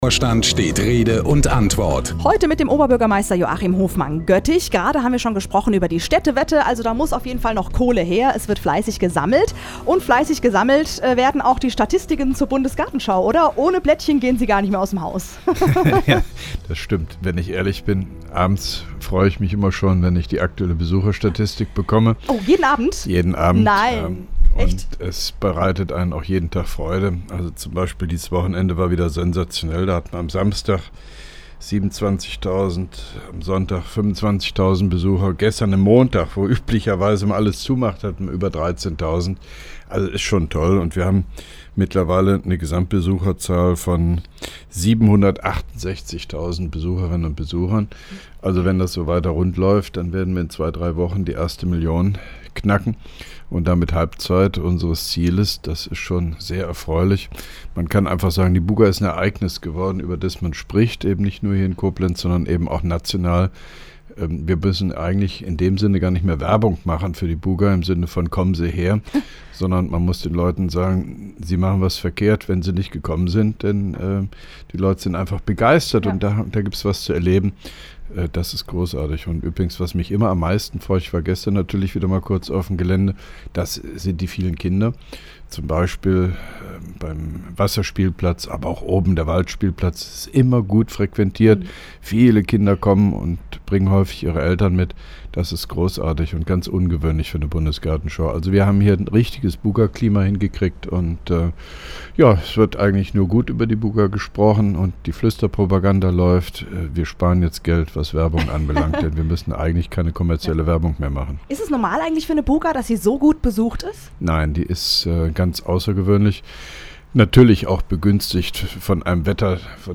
(2) Koblenzer Radio-Bürgersprechstunde mit OB Hofmann-Göttig 31.05.2011